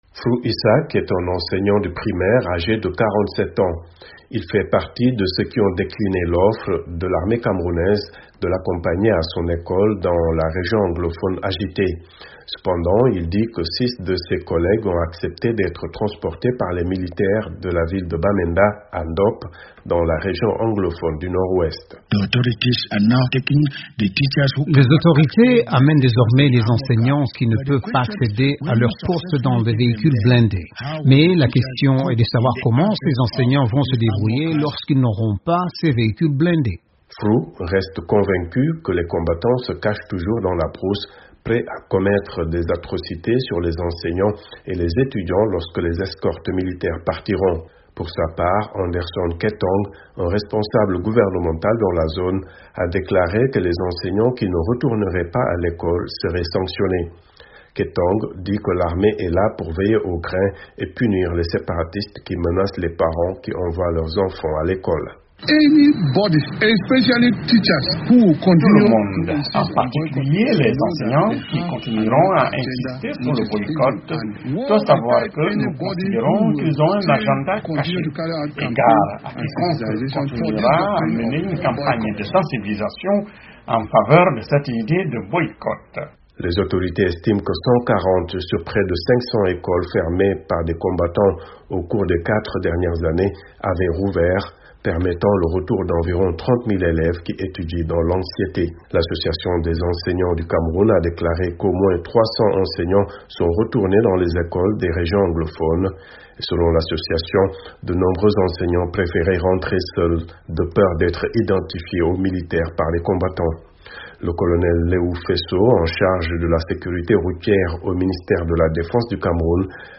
Les enseignants disent craindre pour leur sécurité puisque les séparatistes menacent ceux qui retourneraient dans les salles de classe. Reportage à Yaoundé